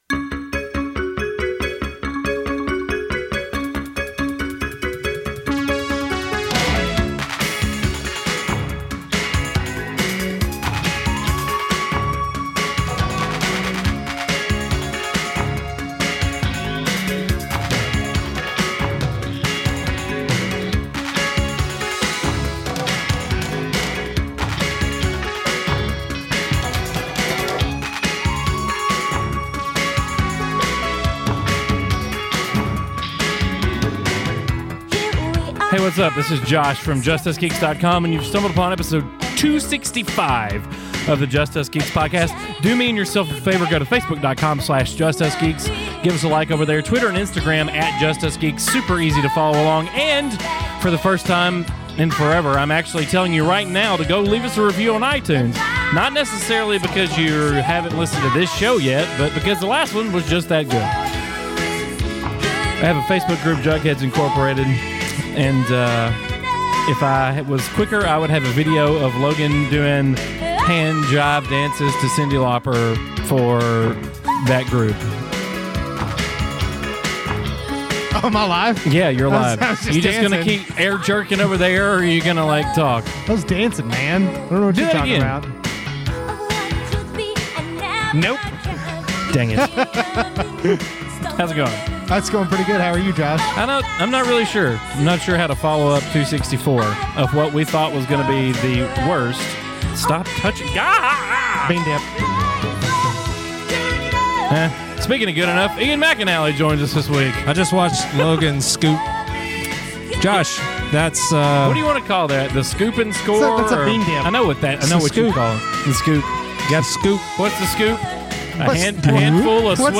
This episode really isn’t about Barry Manilow. Well, we sort of do some random karaoke if that counts?